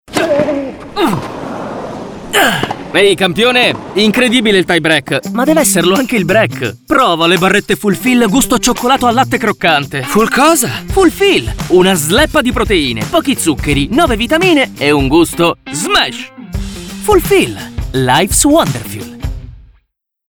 Ironico